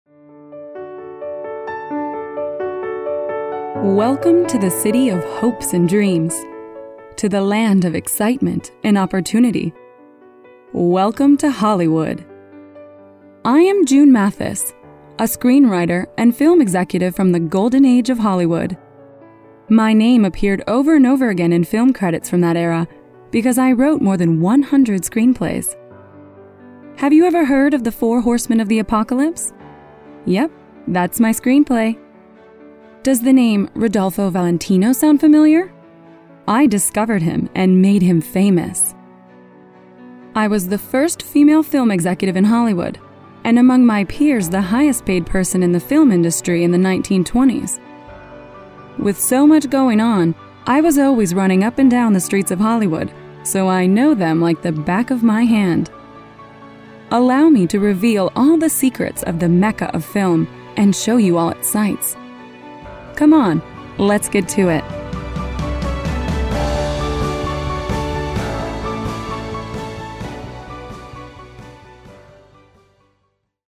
Inglés (Americano)
Joven, Cool, Versátil, Seguro, Cálida
Audioguía